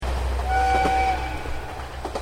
磐越東線の要田−三春間で録音。この車輌は気動車ですが、音的には
ホイッスルなので、こちらに載せました。